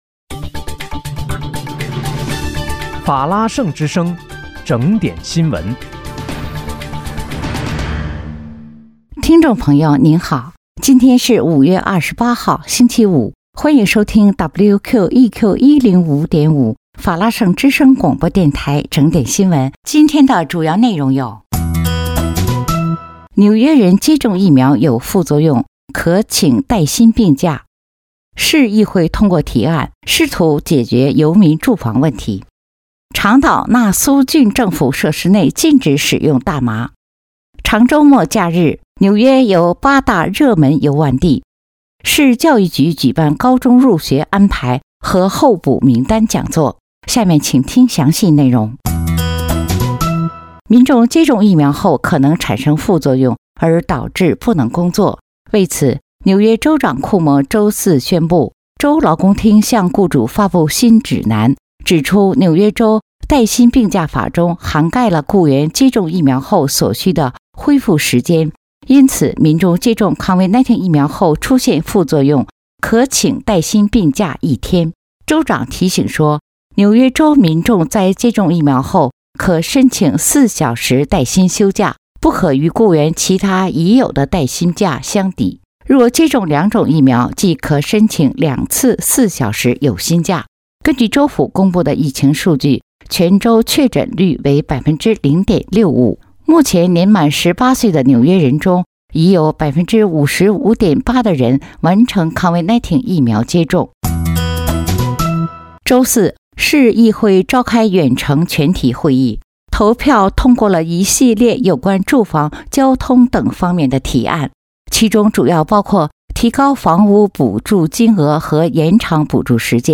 5月28日（星期五）纽约整点新闻